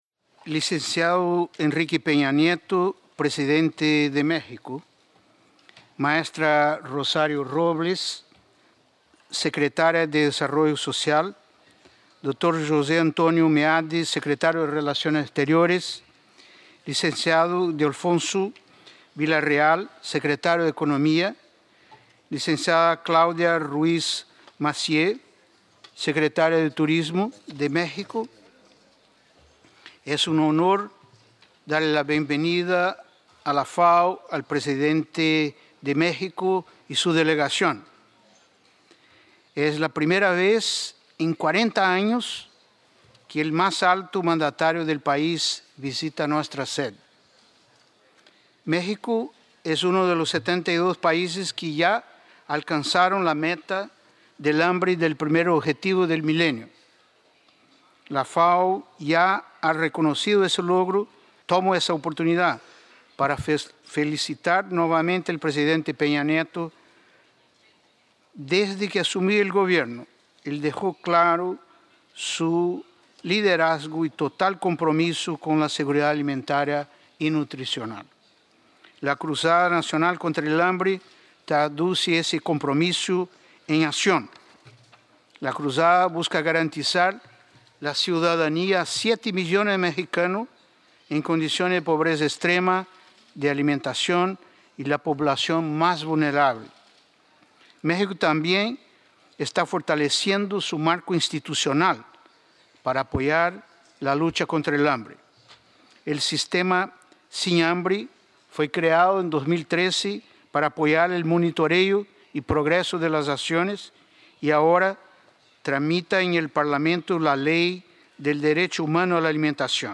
13 de junio de 2015, Roma--- El Excelentísimo Señor Enrique Peña Nieto, Presidente de México, y el Excelentísimo Señor Juan Manuel Santos Calderón, Presidente de Colombia, se dirigieron a la sesión de clausura de la Conferencia de la FAO, el más alto órgano de gobierno de la Organización, tras asistir a la cumbre de la Unión Europea-Comunidad de Estados Latinoamericanos y Caribeños (CELAC) celebrada en Bruselas y a su vuelta de la Expo Milán 2015.
José Graziano da Silva, Director General de la FAO.